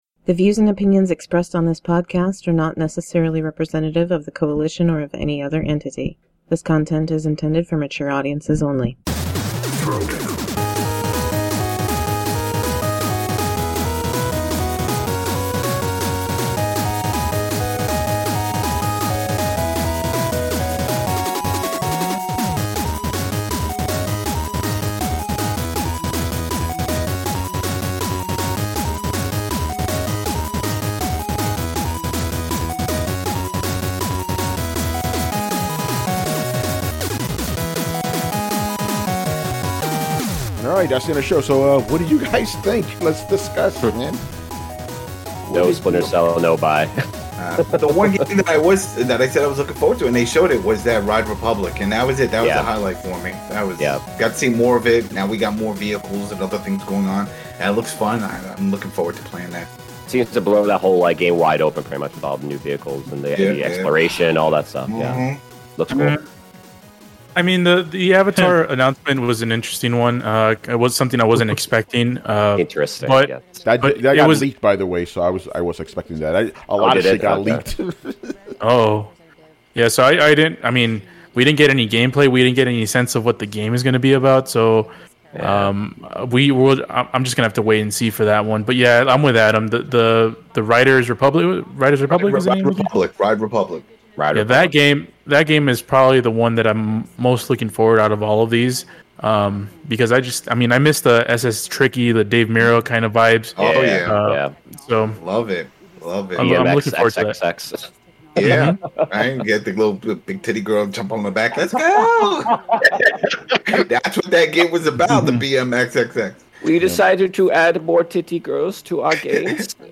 our intro and outro music